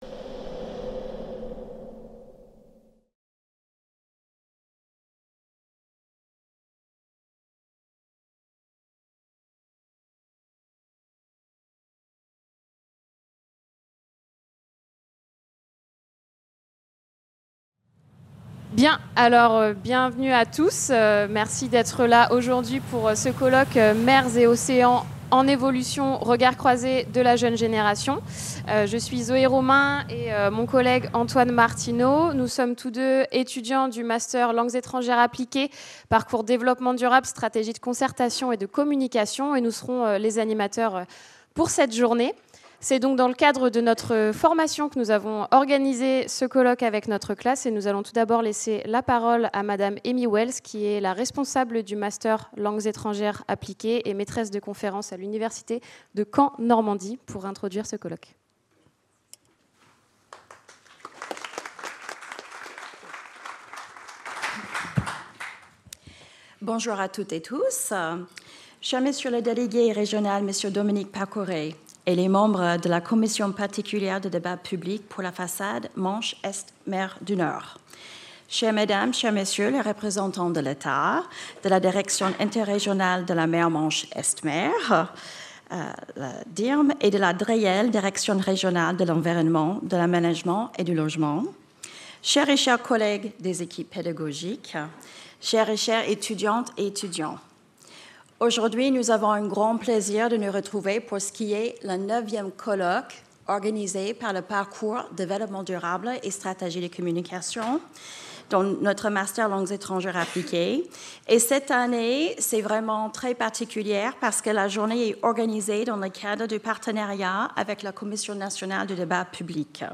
Mers et océans en évolution - Allocution d'ouverture | Canal U
Le mercredi 6 mars 2024 s’est déroulé le colloque organisé par les étudiants du master Développement durable : stratégies de concertation et de communication (DDSC). Cette année, il portait sur l’avenir des mers et océans dans le cadre du débat national en cours : “LA MER EN DÉBAT”.